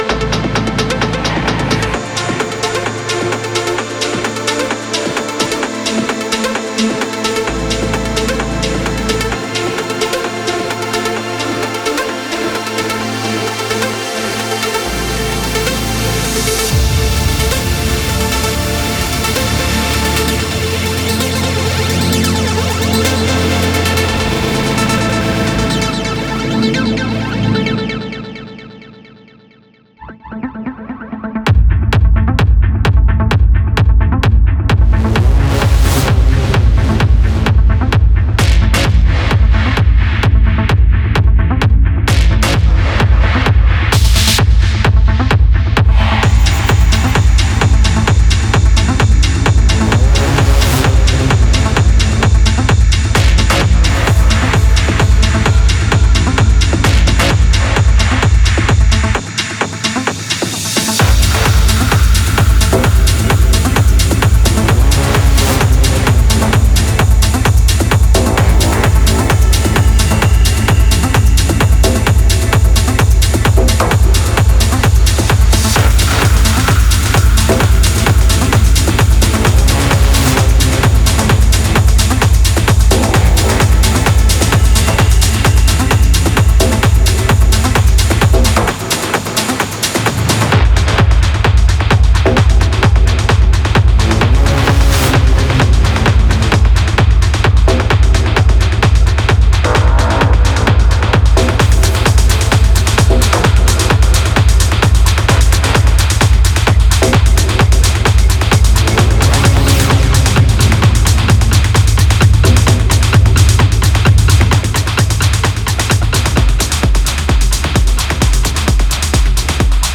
Electronic
Techno ← Előző KövetK →